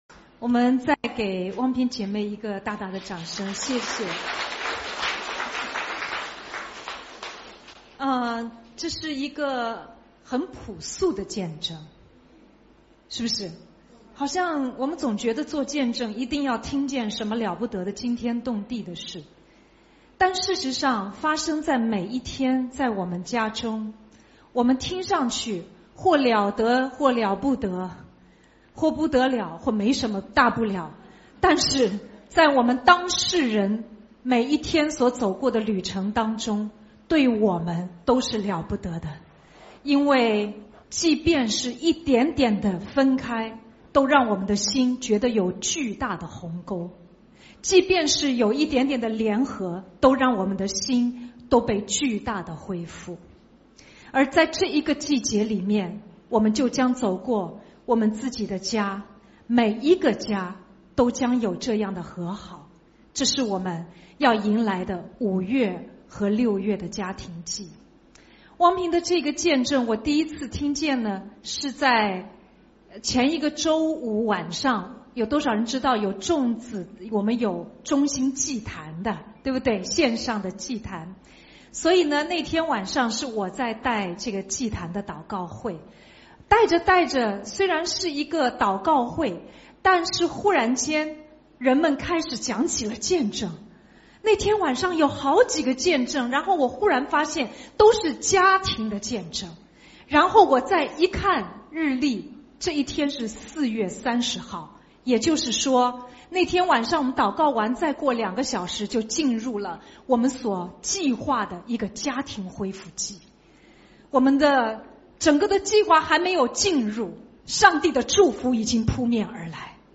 几位牧师：关于家庭的讲道